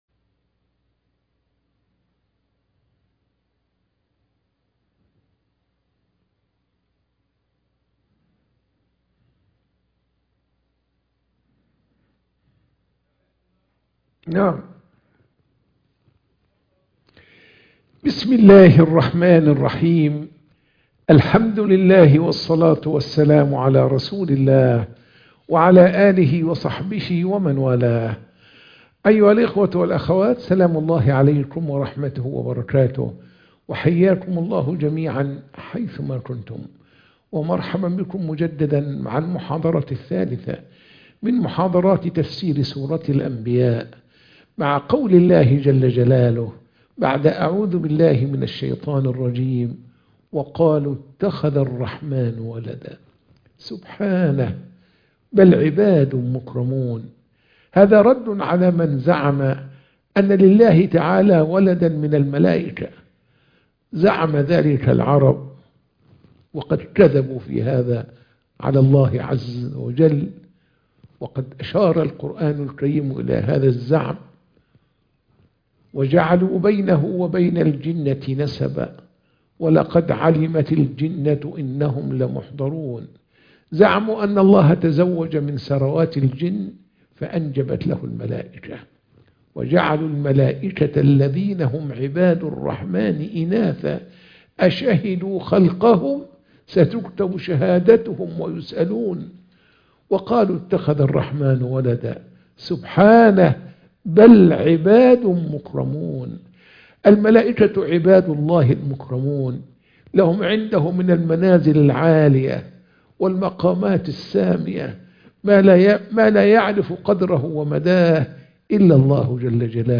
محاضرة التفسير - سورة الأنبياء